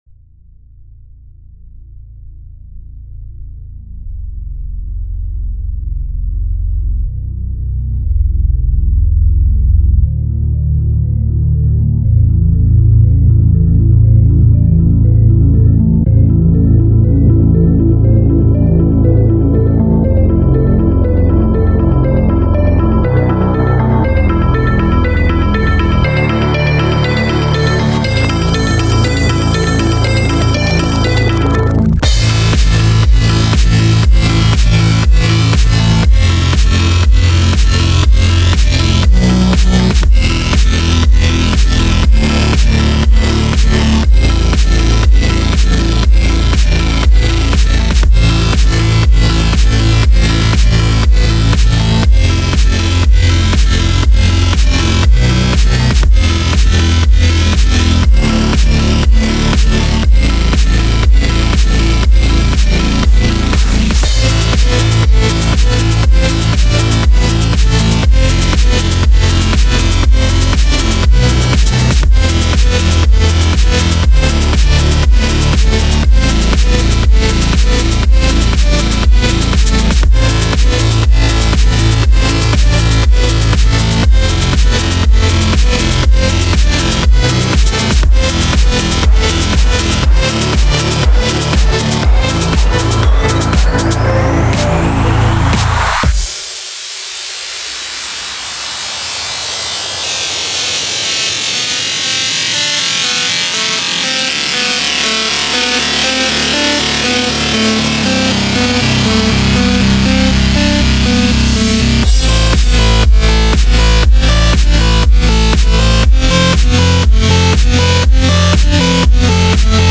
pure electro sound